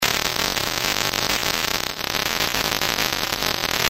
AC Buzz " ACbuzz 02
描述：完美（样本精确）1秒钟的合成电源线嗡嗡声，50 Hz。 使用ZynAddSubFX，LMMS和Audacity创建。
标签： 线 SFX 嗡嗡声 ZAP 游戏 哼哼 电力 电力 电气 噪音
声道立体声